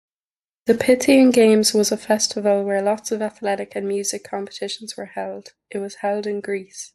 A narrator briefly introduces several famous festivals from history, sharing when and where they took place.